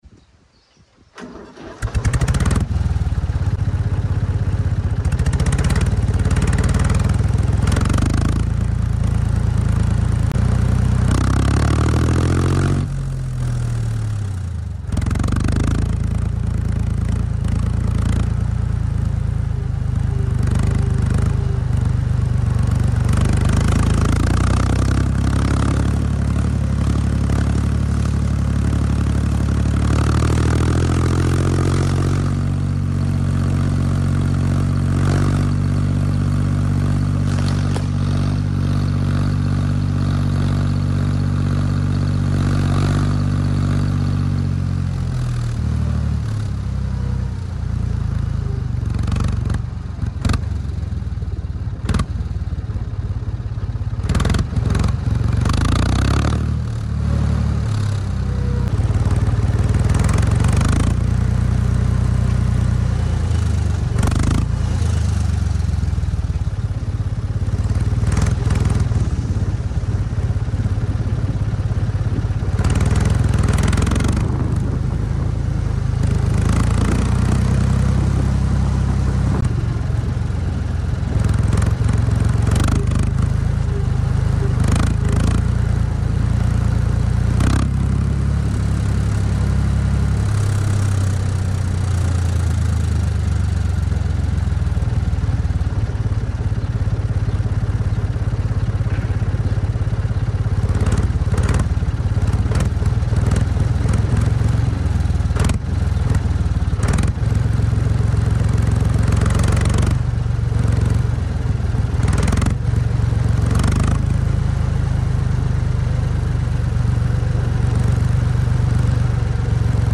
Pure Sound Deutz 52 06#deutz sound effects free download